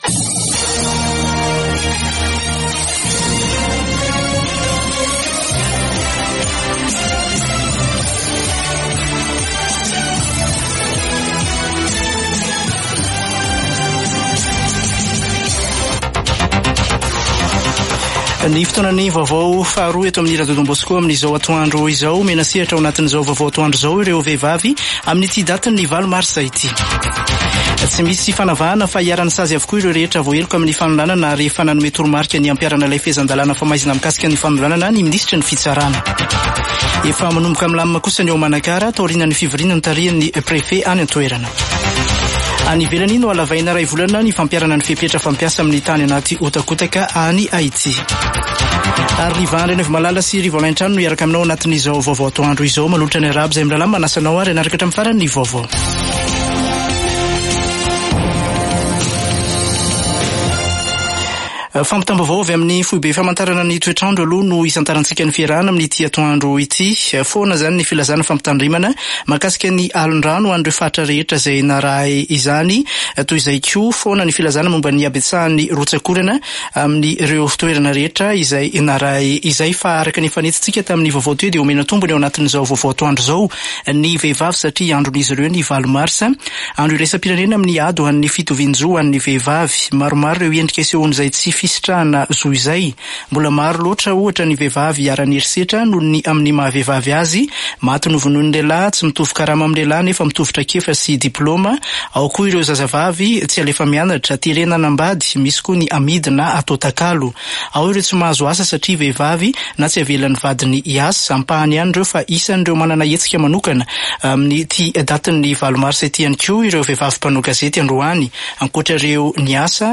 [Vaovao antoandro] Zoma 8 marsa 2024